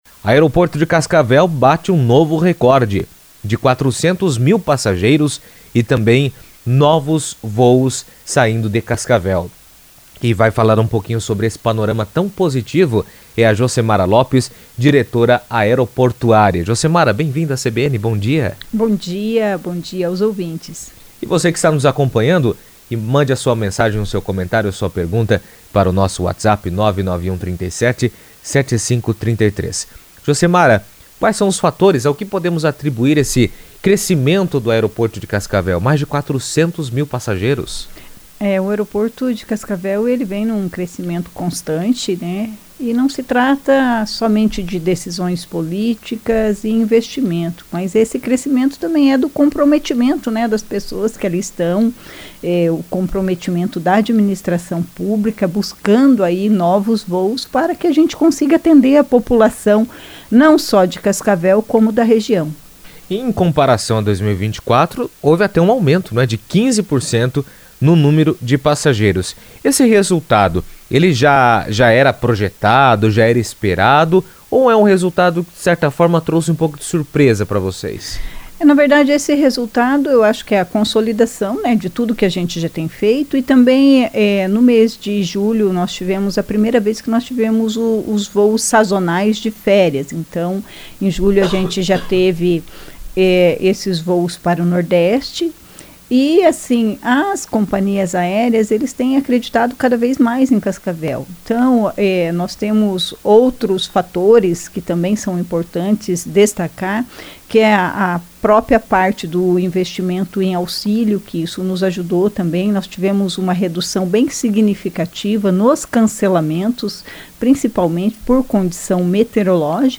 O Aeroporto Regional de Cascavel atingiu a marca de 400 mil passageiros, registrando um recorde histórico na movimentação de passageiros. O crescimento reflete a expansão dos voos e o fortalecimento da cidade como hub regional de transporte aéreo. Em entrevista à CBN Cascavel